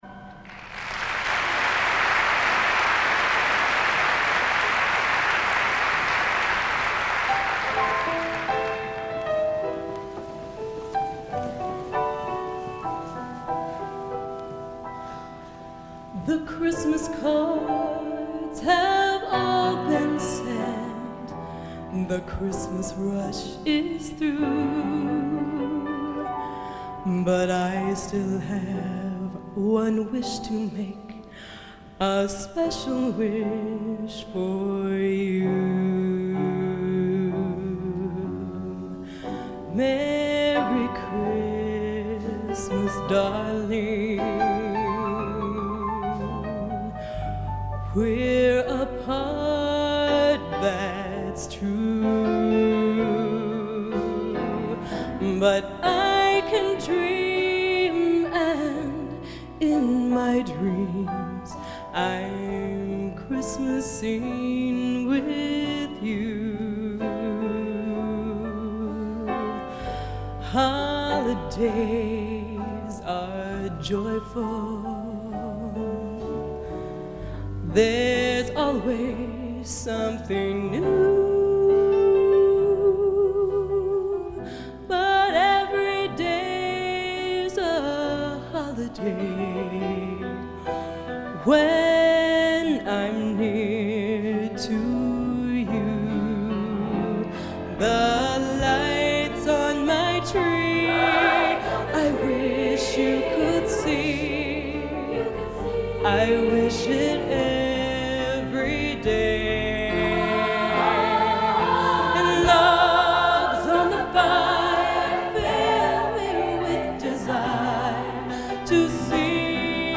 Genre: | Type: Christmas Show |